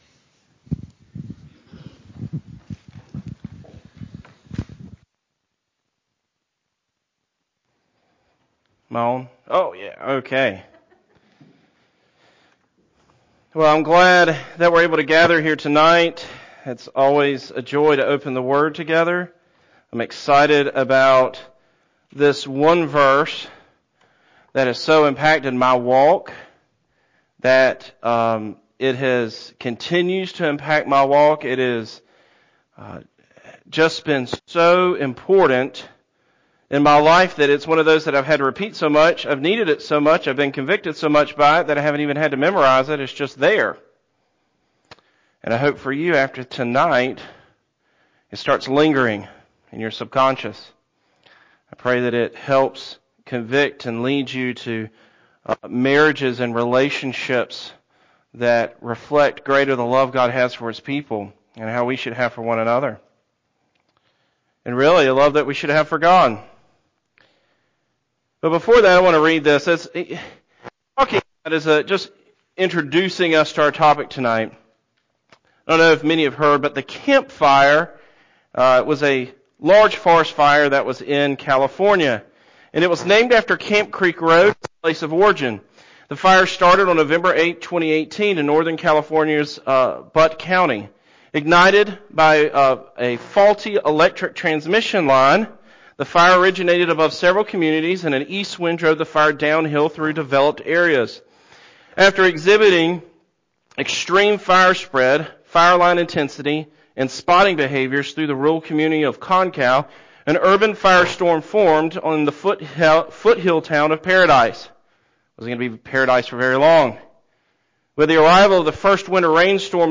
Sunday Night Teaching